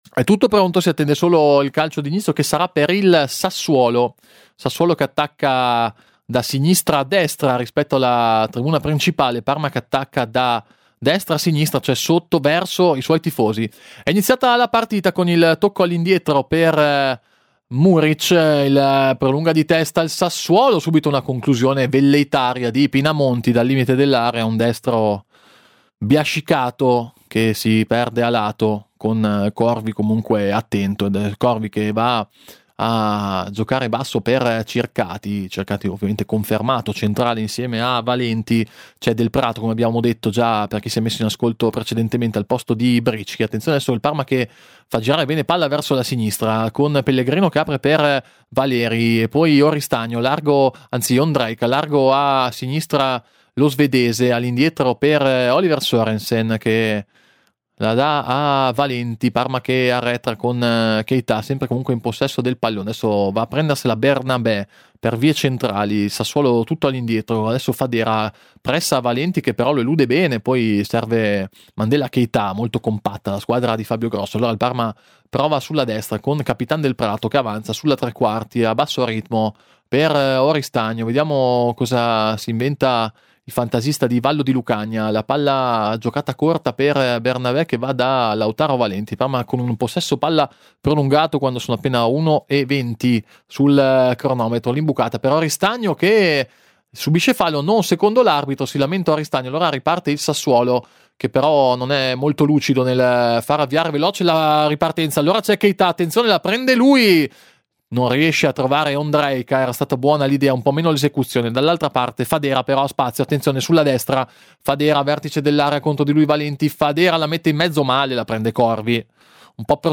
I crociati con il Sassuolo sono alla ricerca di conferme. Radiocronaca